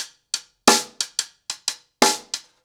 Wireless-90BPM.15.wav